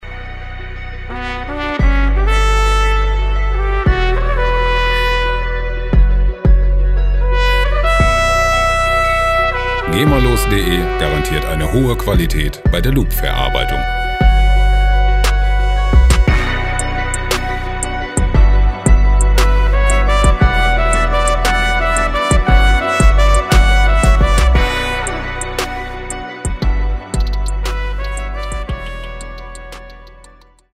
lizenzfreie Latin Musik
Musikstil: Latin Trap
Tempo: 116 bpm